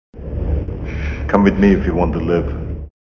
Tags: Arnold Schwarzenneger sound prank call voice